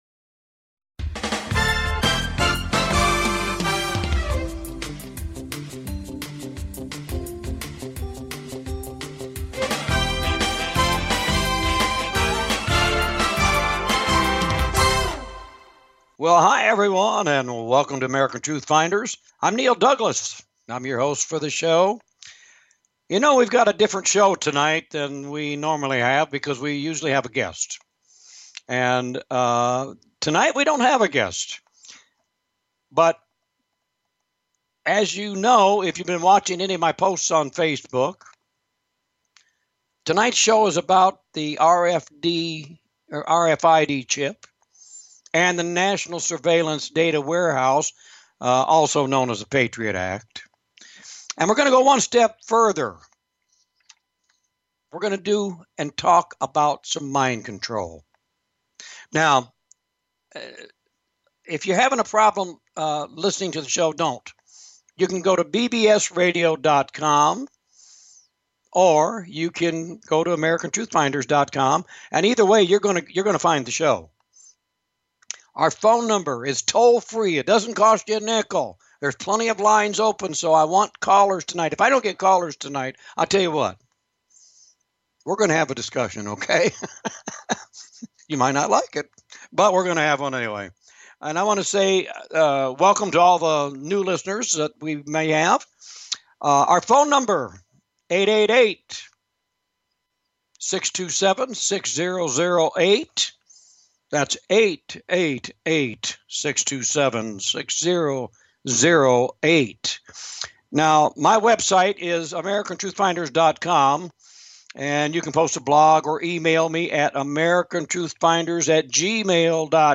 Talk Show Episode, Audio Podcast, American Truth Finders and RFID CHIPS AND NATIONAL SURVEILLELANCE DATA WAREHOUSE (AKA PATROIT ACT) on , show guests , about RFID,RFID Chips,National Surveillance Data Warehouse,Patriot Act, categorized as Earth & Space,News,Philosophy,Politics & Government,Theory & Conspiracy